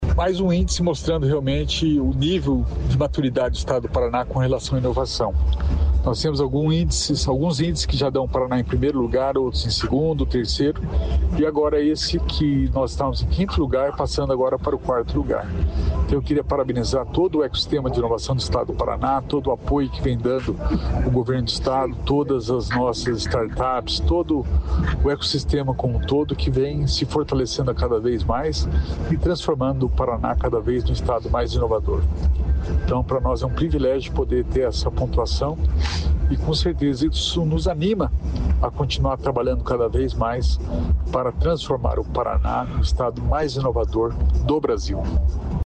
Sonora do secretário da Inovação e Inteligência Artificial, Alex Canziani, sobre o Ranking de Competitividade dos Estados